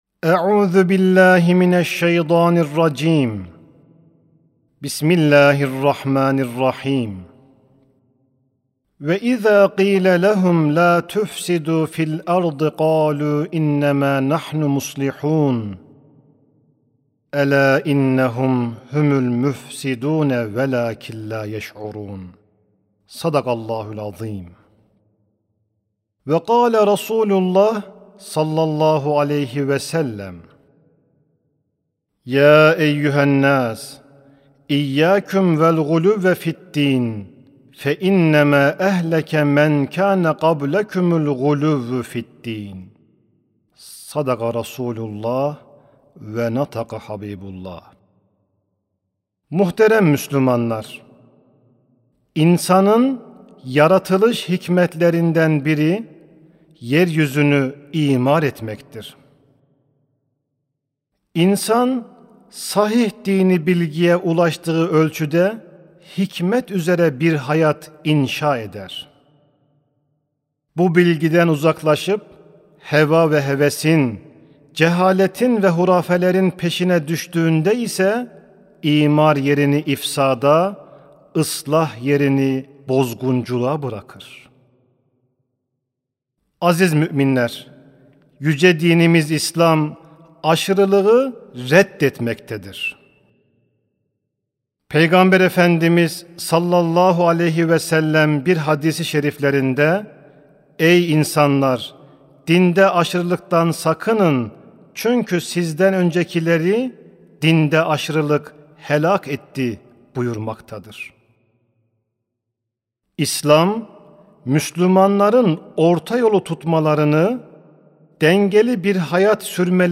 Sesli Hutbe (Din İstismarı).mp3